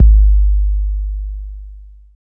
Kick OS 10.wav